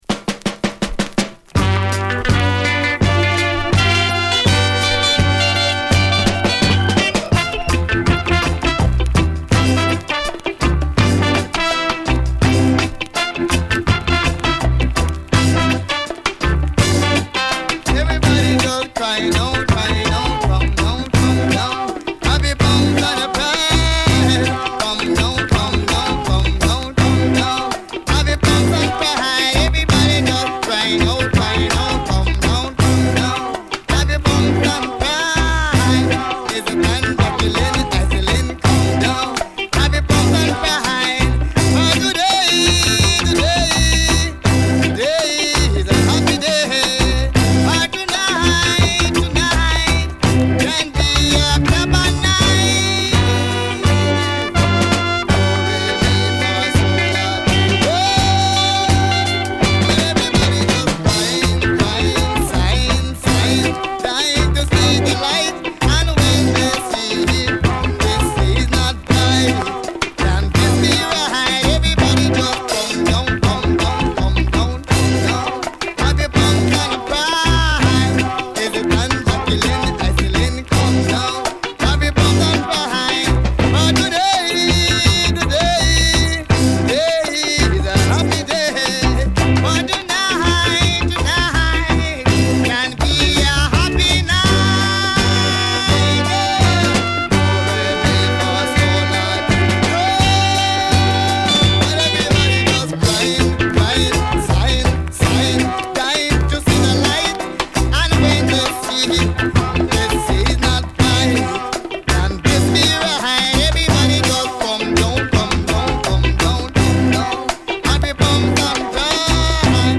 今回はソウルフルかつメロウなレゲエをセレクト＆ミックス！
＊試聴は前半部3曲です。